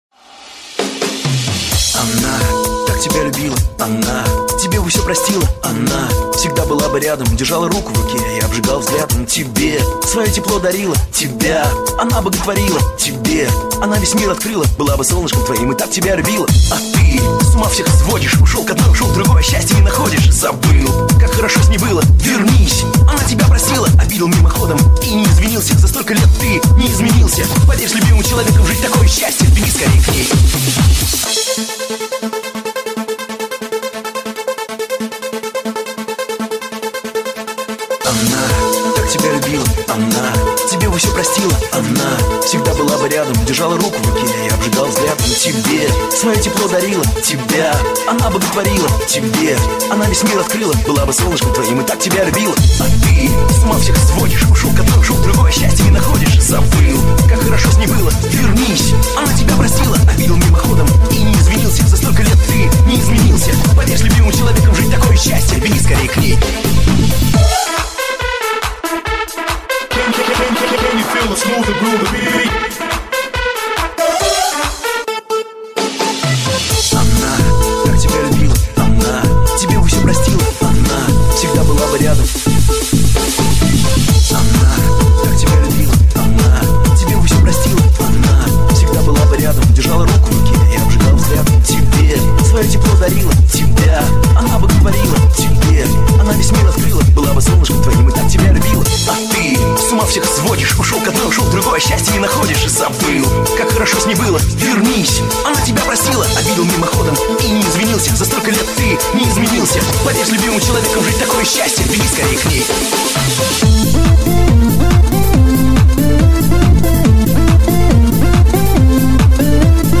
Зона обмена: Музыка | Русская ПОПса